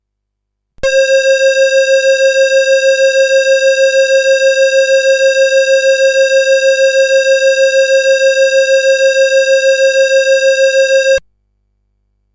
改良前のソフトで記録した実際の音声(出だしの部分)
音の出始めが少し割れたように聞こえます。